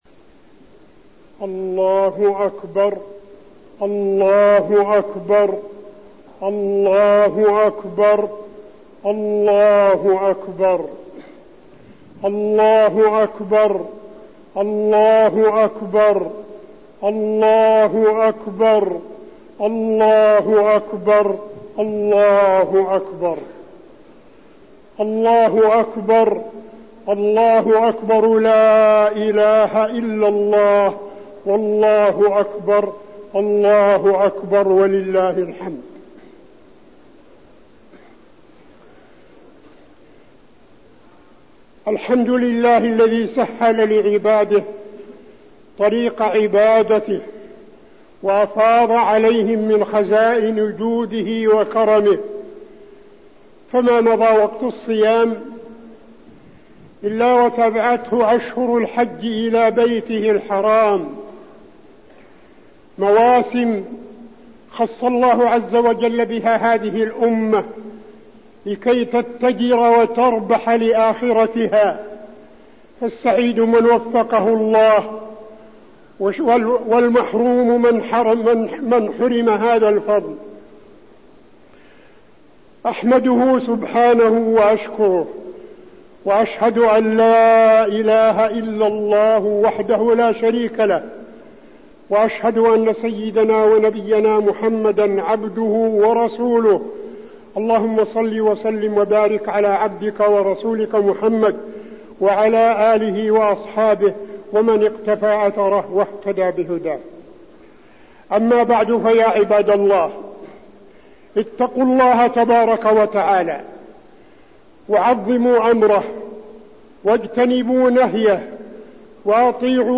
خطبة عيد الفطر - المدينة - الشيخ عبدالعزيز بن صالح
تاريخ النشر ١ شوال ١٤١١ هـ المكان: المسجد النبوي الشيخ: فضيلة الشيخ عبدالعزيز بن صالح فضيلة الشيخ عبدالعزيز بن صالح خطبة عيد الفطر - المدينة - الشيخ عبدالعزيز بن صالح